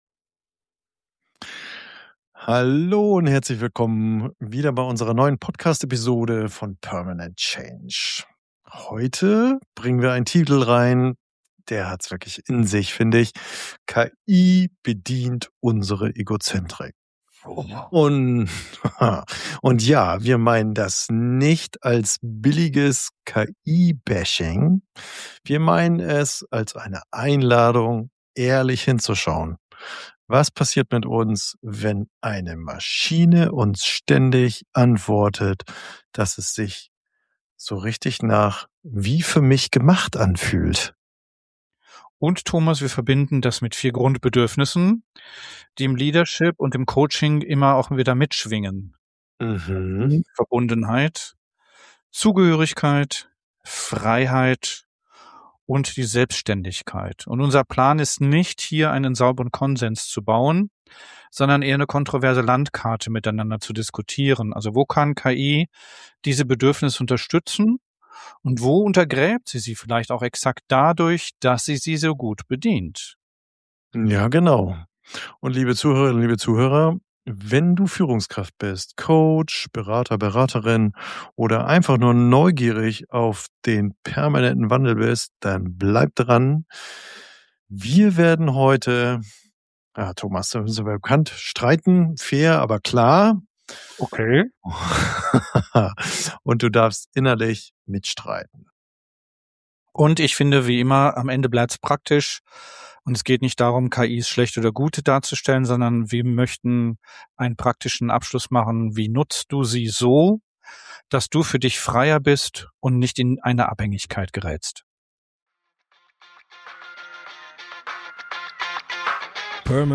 In dieser diskursiven, konfrontativen Episode streiten wir darüber, warum generative KI sich oft wie ein ‚Spiegel, der zurückspricht‘ anfühlt – und was das mit unseren psychoemotionalen Grundbedürfnissen Verbundenheit, Zugehörigkeit, Freiheit und Selbständigkeit macht. Wir verbinden Philosophie, Psychologie, Neurobiologie und Transaktionsanalyse: von ‚Begegnung vs. synthetische Nähe‘ über Strokes, Ego-States (Eltern–Erwachsenen–Kind) bis zu der Frage, wie du KI so nutzt, dass sie Autonomie und Urteilsfähigkeit stärkt, statt nur schnelle Bestätigung zu liefern.